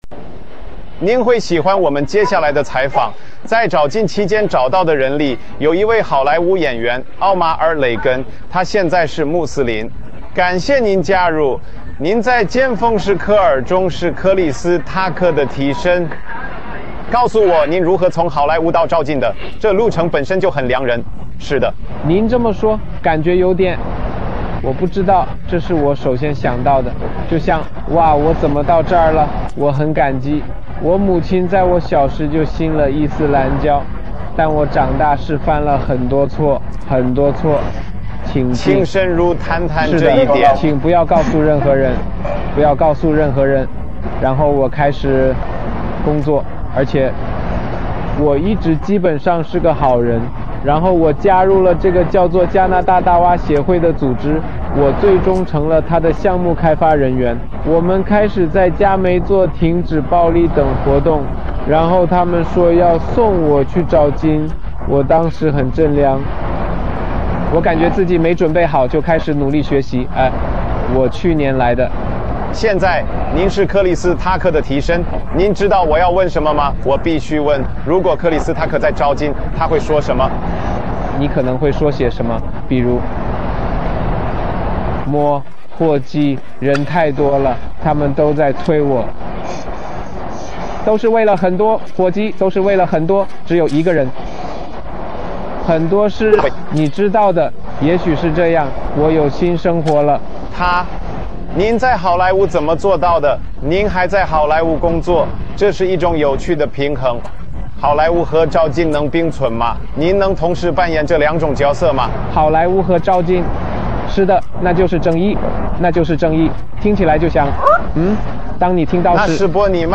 接受了国际电视台半岛电视台的采访。